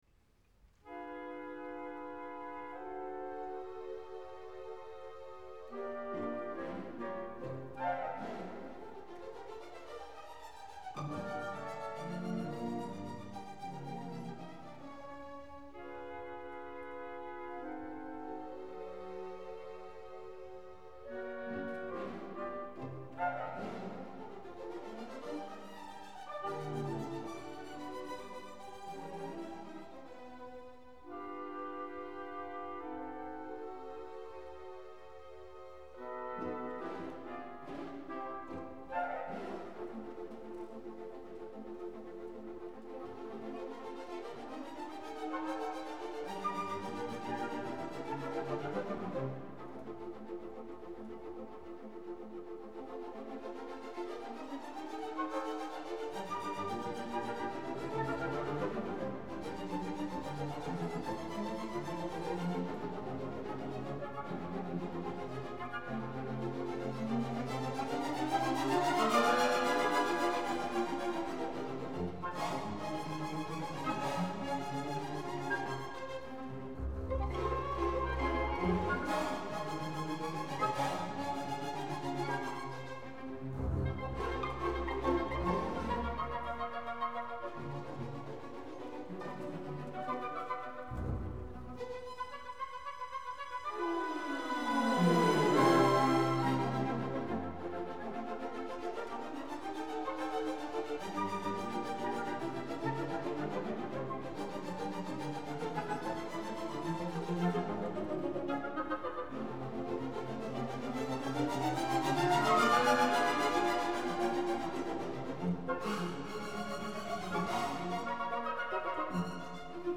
extrait 2 (écoutez le traitement orchestral de ce scherzo)